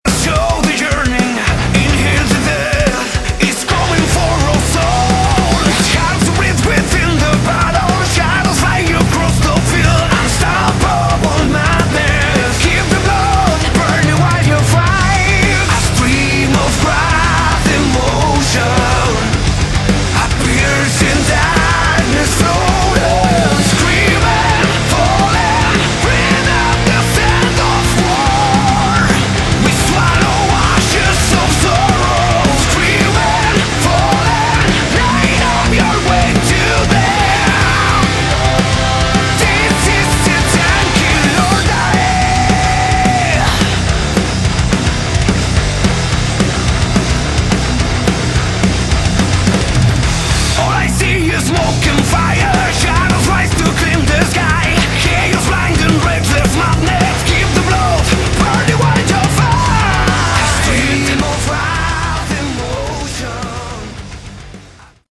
Category: Melodic Metal
Vocals
Guitar, vocals, keyboards
Bass, vocals
Drums